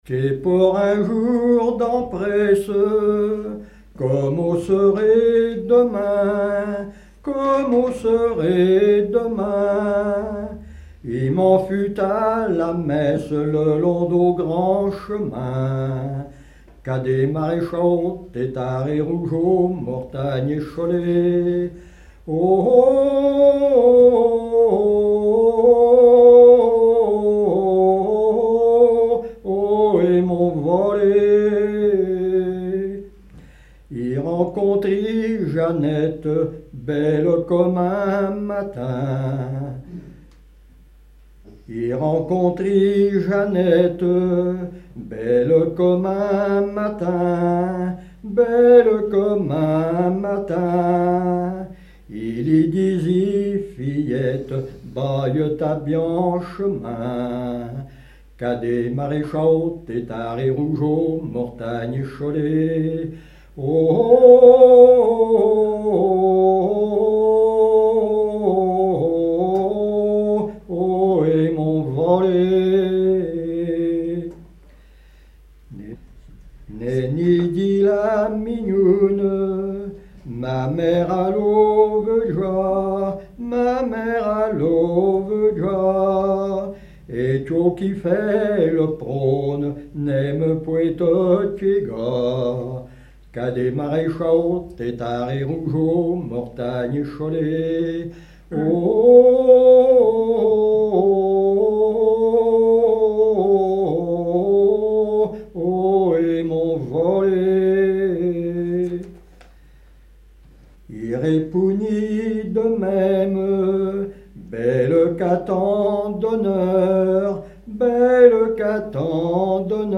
Genre laisse
Veillée (version Revox)